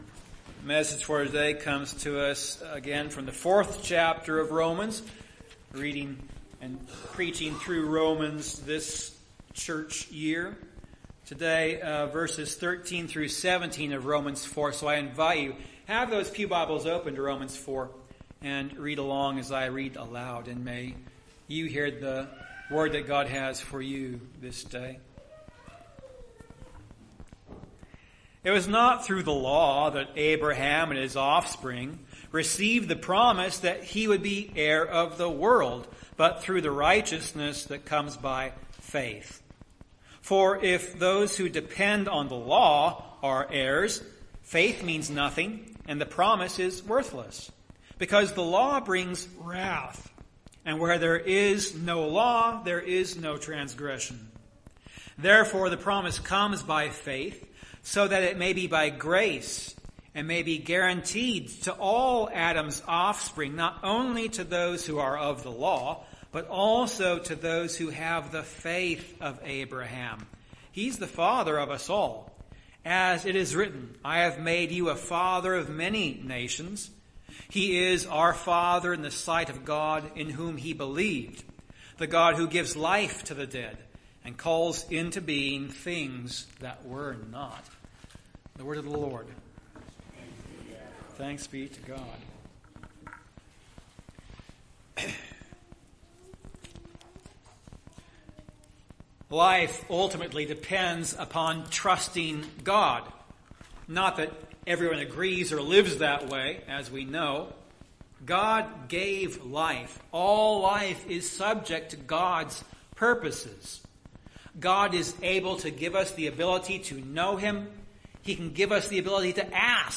Romans 4:13-17 Service Type: Communion Service Life ultimately depends upon trusting God.